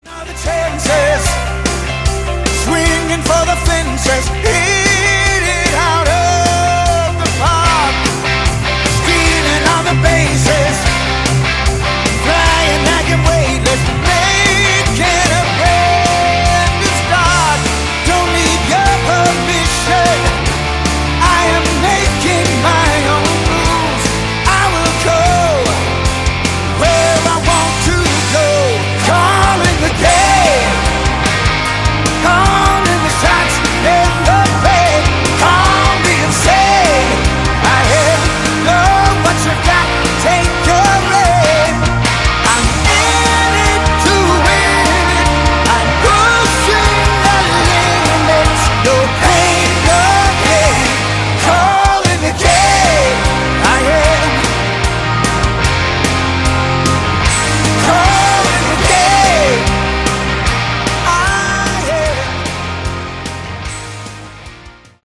Category: AOR
lead vocals
lead and rhythm guitar, bass, keyboards, backing vocals
drums
Hammond B3, piano, analog synthesizers